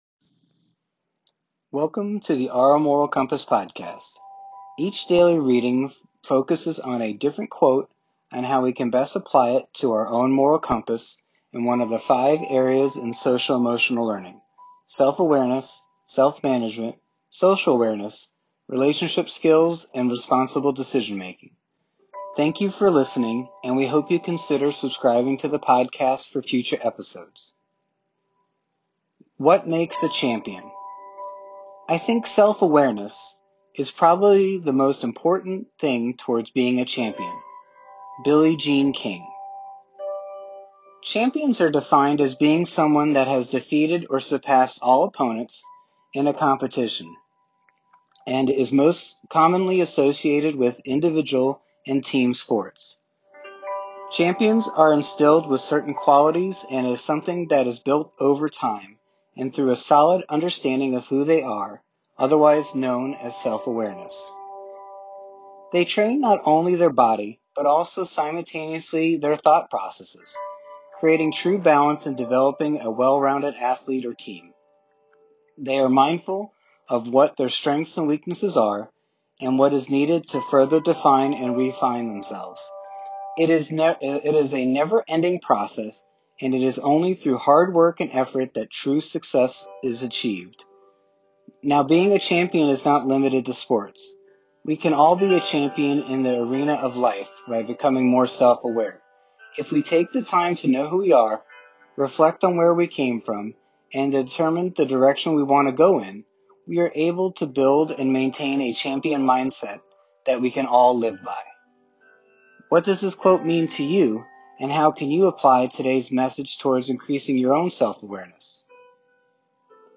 Each daily reading focuses on a different quote on how we can best apply it to our own moral compass and one of the five areas in Social Emotional Learning: Self-Awareness, Self-Management, Social Awareness, Relationship Skills and Responsible Decision Making.